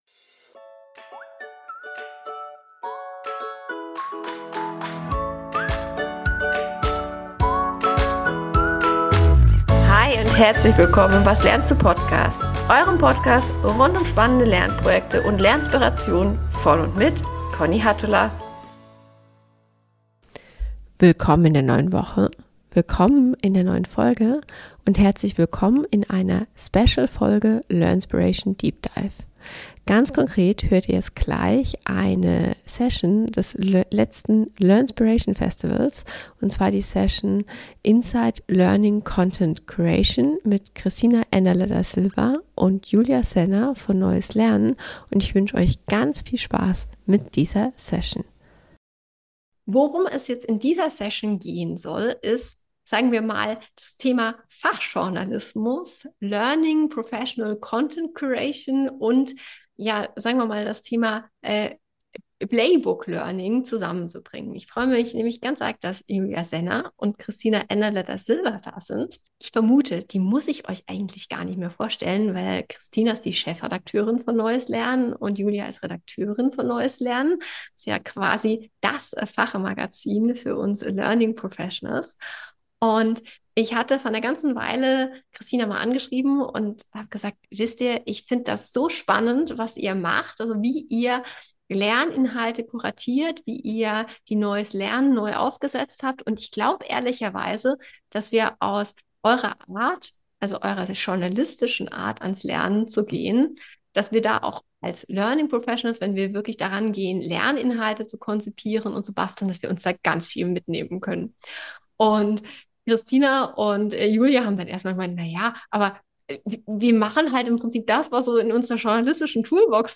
#136 - Journalismus trifft L&D: Lernen kuratieren wie ein Magazin (live vom Learnspiration Festival) ~ Was lernst Du?